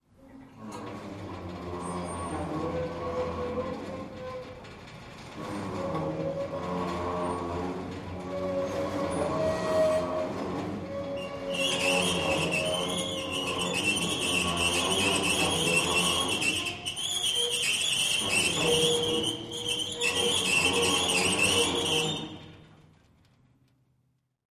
Stage Curtain: Pulley Squeaks.